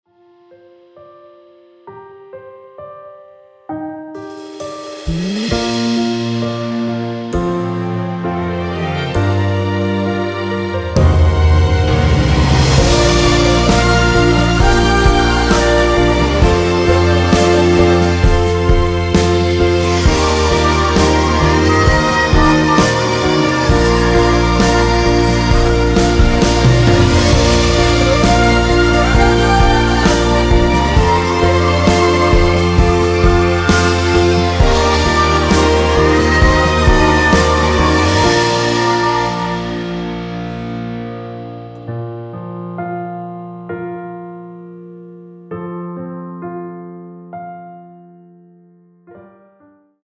미리듣기에 나오는 부분이 코러스로 추가되었습니다.
원키에서(+3)올린 코러스 포함된 MR입니다.
Bb
앞부분30초, 뒷부분30초씩 편집해서 올려 드리고 있습니다.